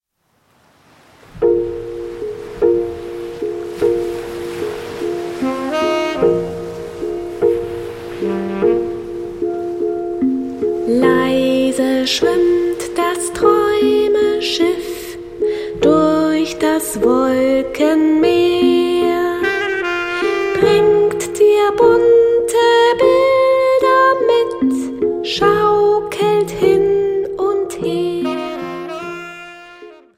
Die schönsten Lieder zur guten Nacht für Kinder